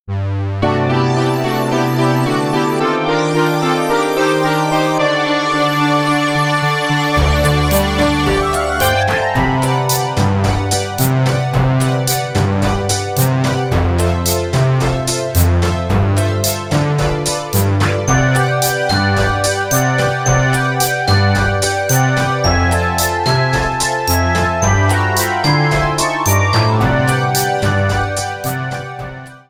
A song
trimmed to 29.5 seconds and faded out the last two seconds